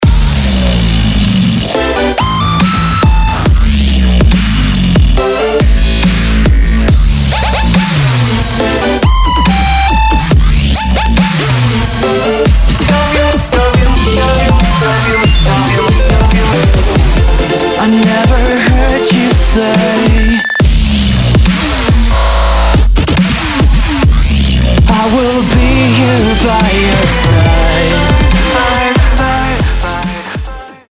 Kategória: Dubstep
Minőség: 320 kbps 44.1 kHz Stereo